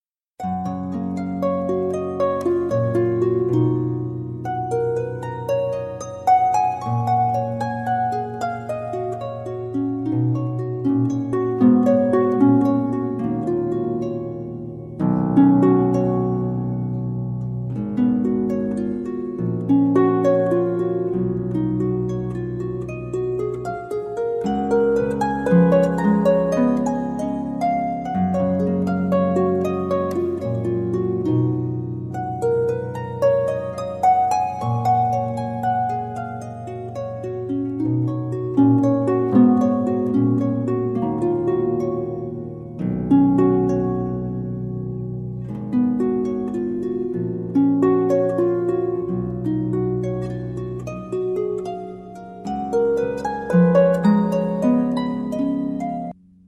HEALING